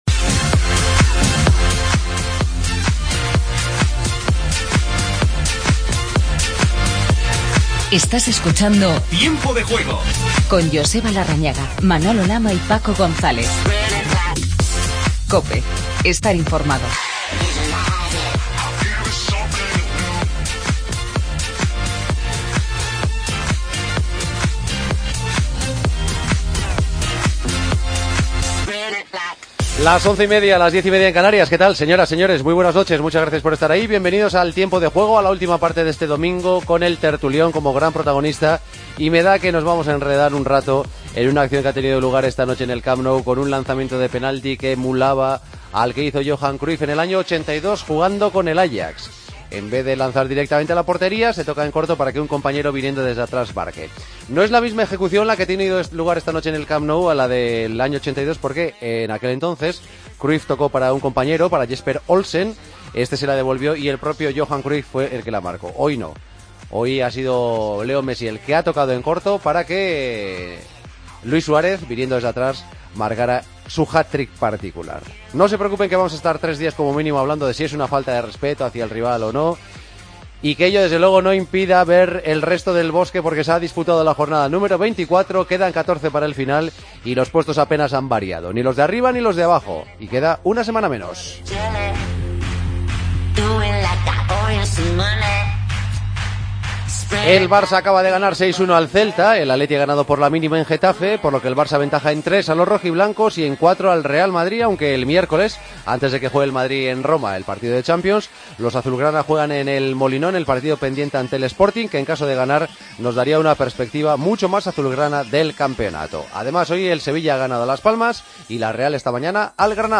El Barcelona golea al Celta con un penalti curioso. Escuchamos a Iniesta y hablamos con Carles Planas. Fernando Torres le dio la victoria al Atlético y hablamos con Juanfran.